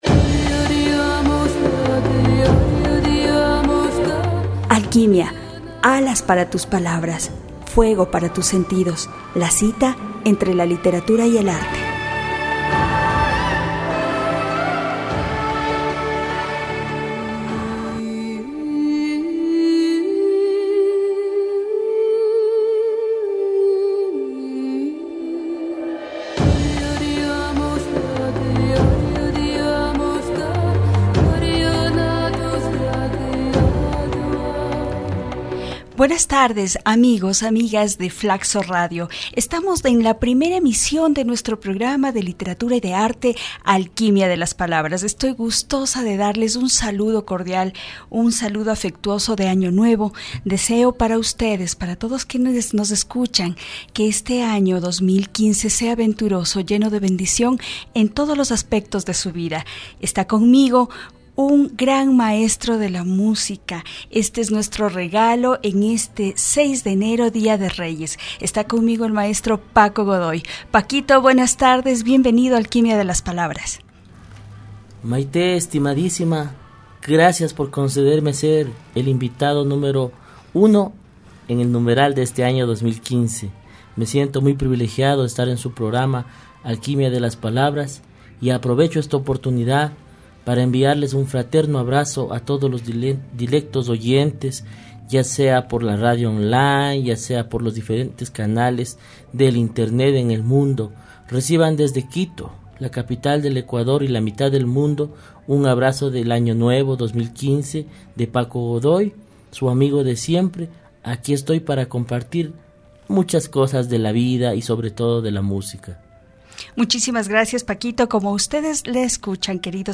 Audio - entrevista ...